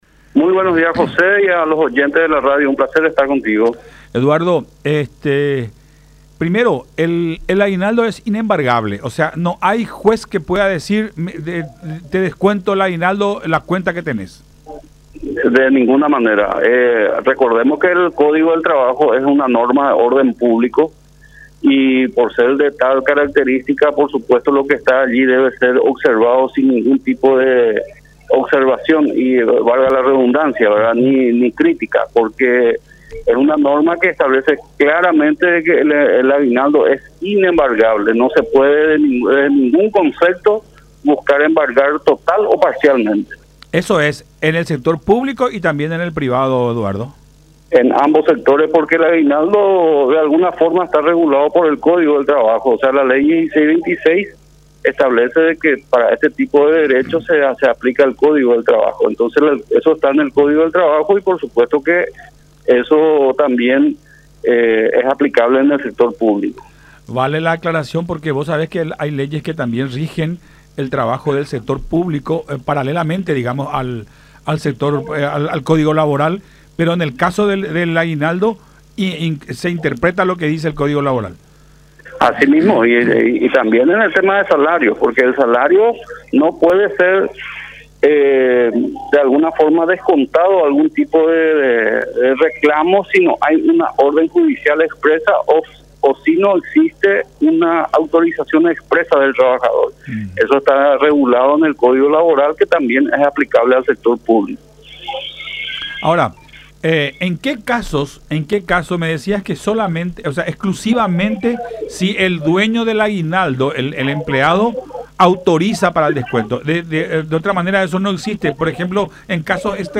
La ley establece que el aguinaldo debe ser abonado íntegramente y no se admite ningún tipo de descuentos”, dijo en conversación con Enfoque 800 por La Unión.